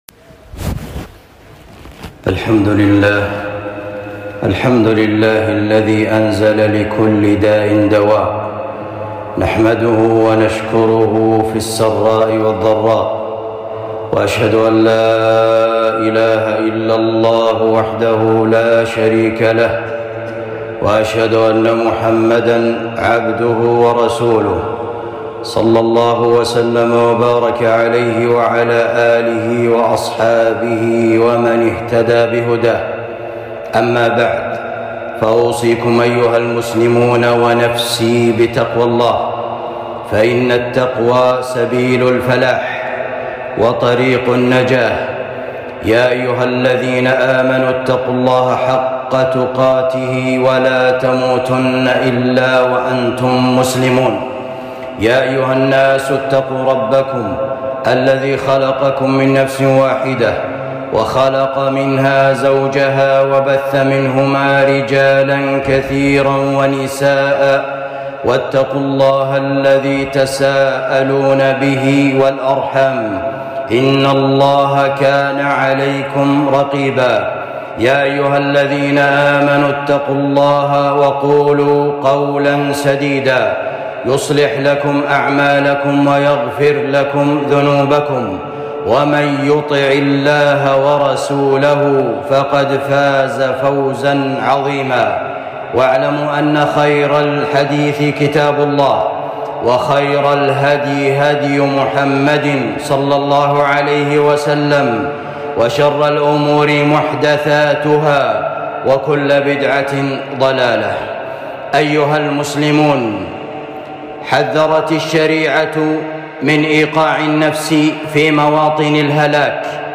خطبة بعنوان التحصن من الأمراض مطلب شرعي